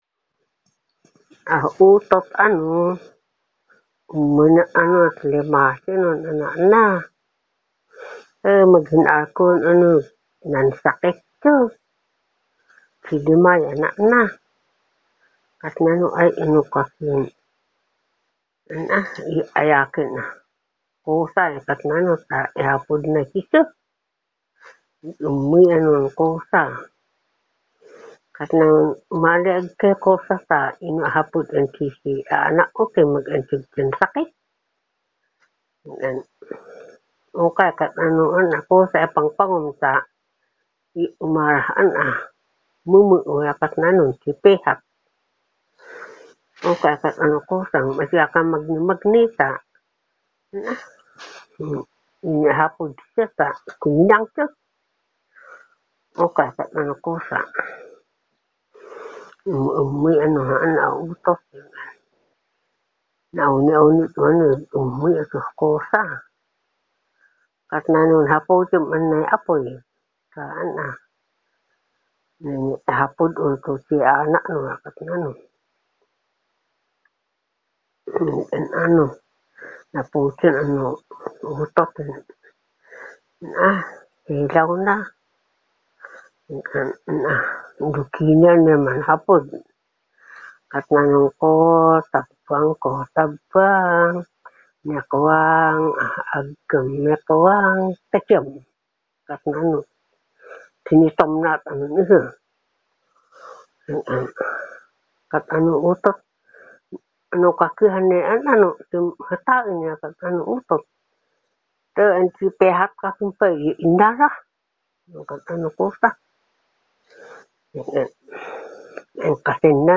Hudhud | Balangao Cultural Website